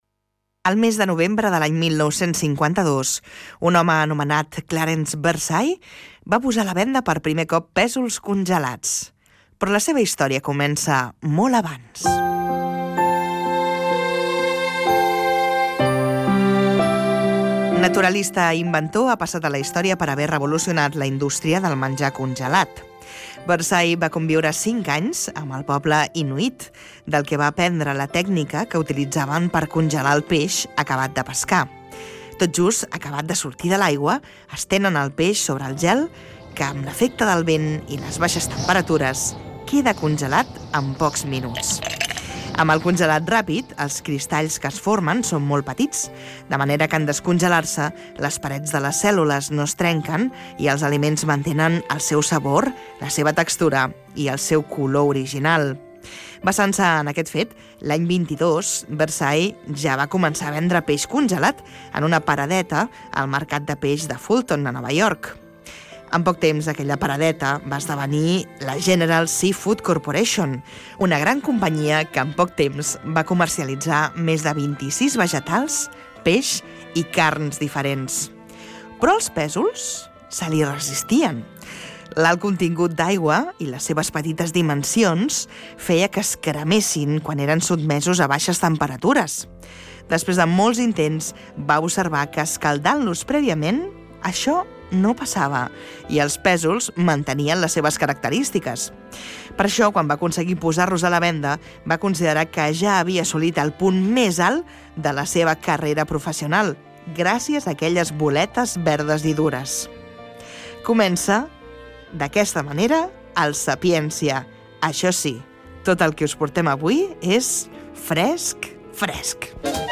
Història dels primers aliments congelats. Careta del programa, sumari, equip i indicatiu
Divulgació